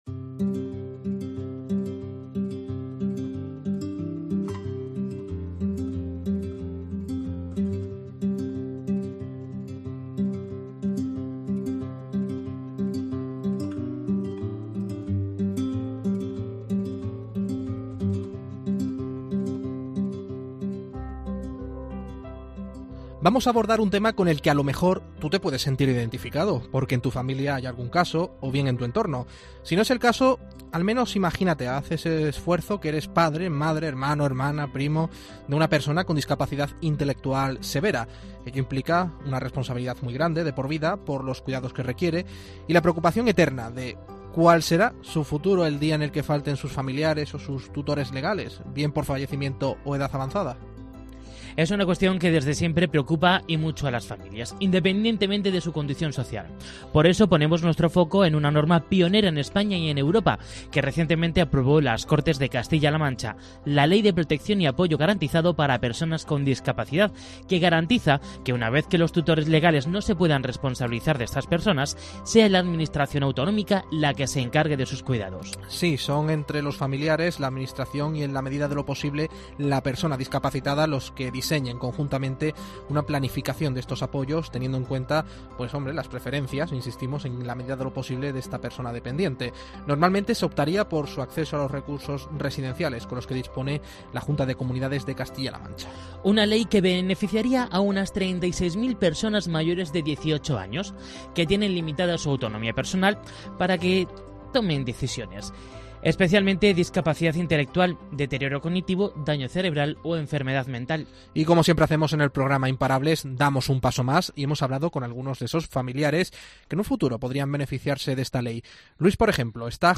Hablamos con familiares a cargo de alguna persona con discapacidad para conocer el futuro que les espera una vez no puedan hacerse cargo de ellos.